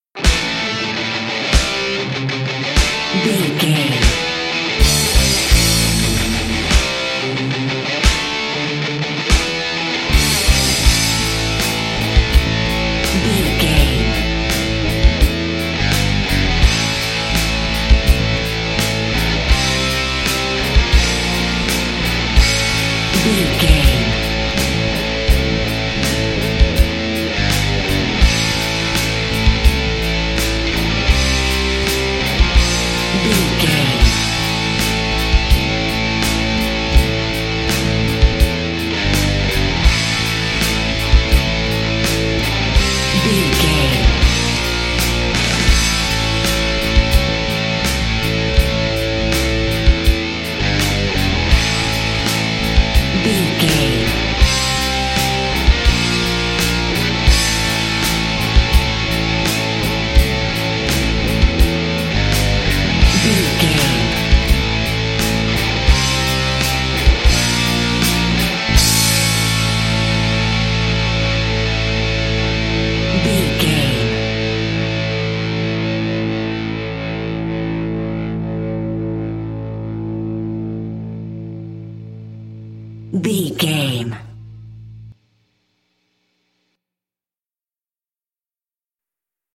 Mixolydian
drums
electric guitar
Sports Rock
hard rock
lead guitar
bass
aggressive
energetic
intense
nu metal
alternative metal